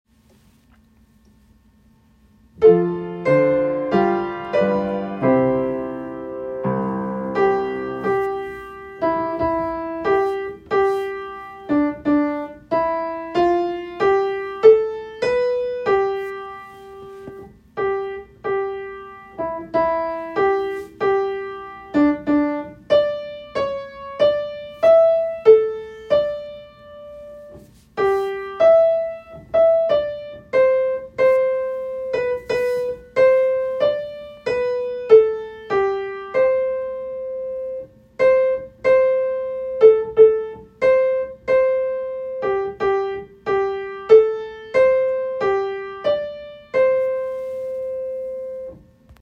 Audition Material (Ages 11-18)
American-the-Beautiful-Melody.m4a